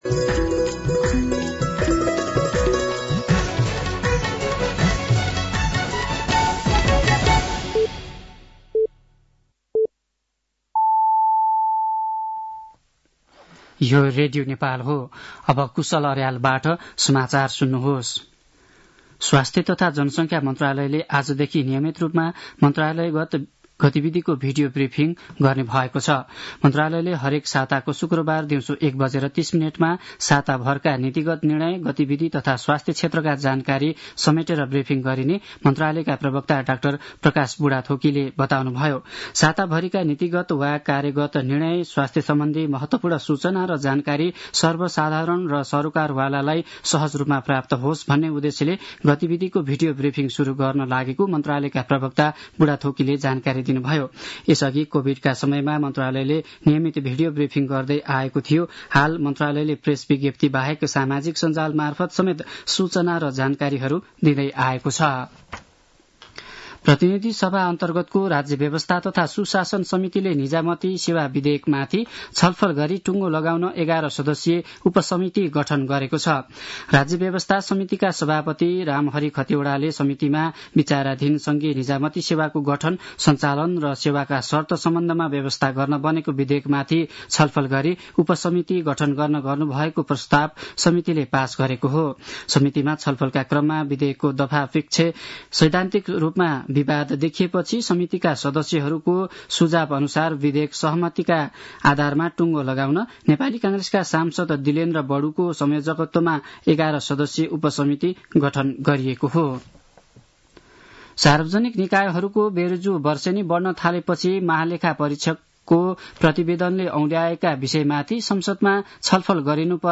साँझ ५ बजेको नेपाली समाचार : १० फागुन , २०८१